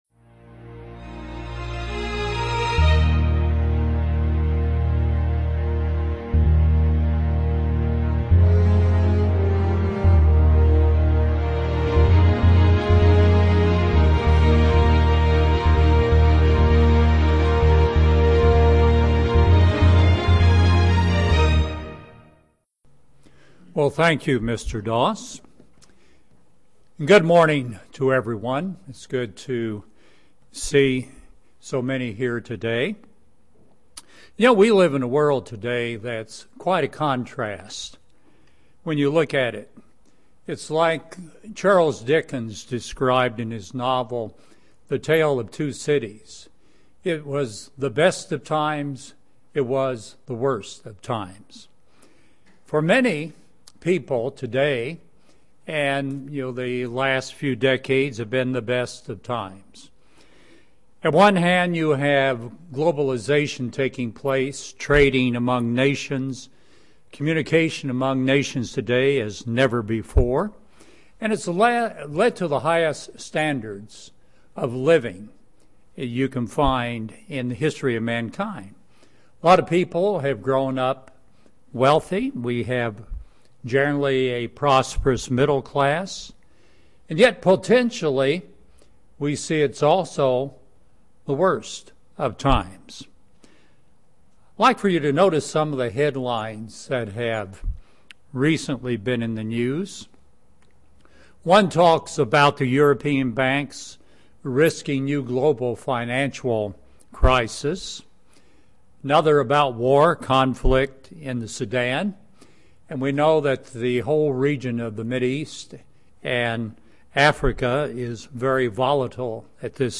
Learn more in this Kingdom of God seminar.